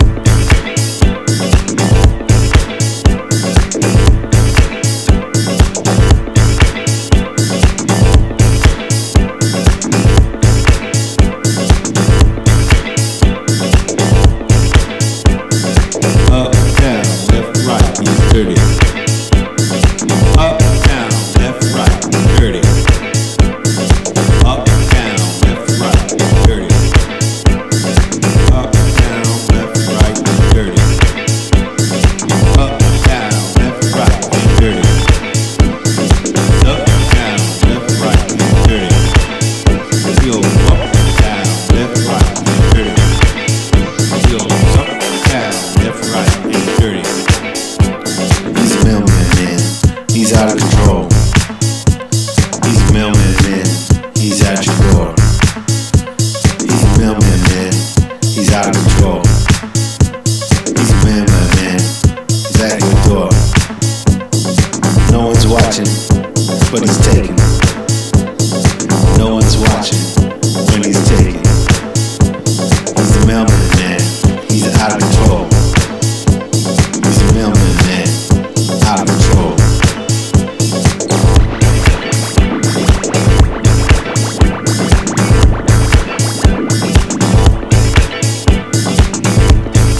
ダーティーでスリージーなエレクトロ・ブギーを展開！
ジャンル(スタイル) NU DISCO / INDIE DANCE / ELECTRONICA